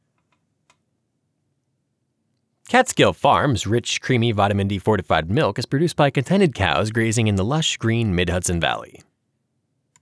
Special Interest Groups Audiobook Production
There’s still some what sounds like air conditioning or wind noise in the background, but it’s so low in volume it doesn’t make any difference.
This is your clip fully mastered with gentle Noise Reduction of the Beast.